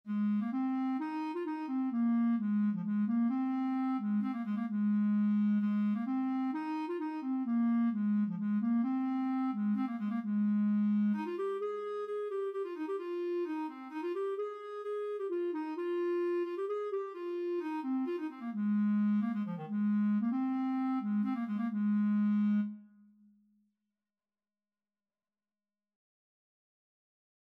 3/4 (View more 3/4 Music)
Eb4-Ab5
Clarinet  (View more Easy Clarinet Music)